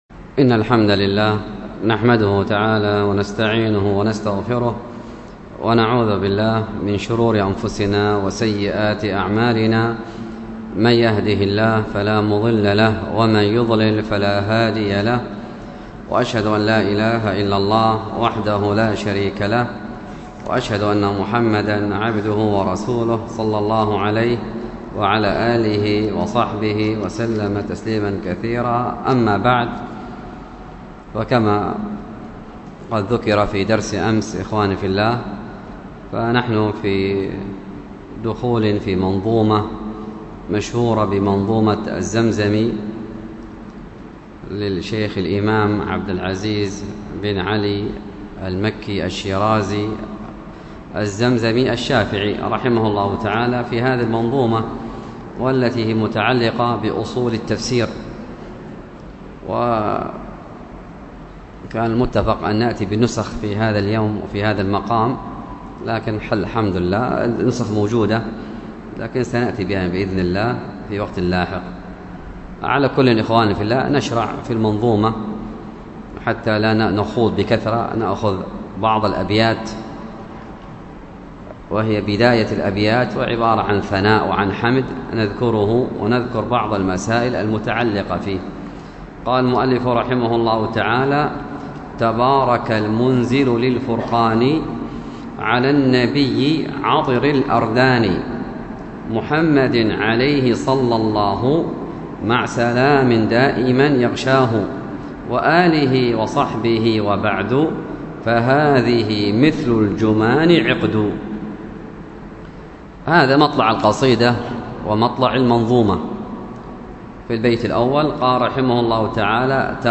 الدرس في شرج كتاب الإيمان من صحيح البخاري 1، الدرس الأول:من ( كتاب الإيمان - باب دعاؤكم إيمانكم ... 12- باب من الدين الفرار من الفتن ).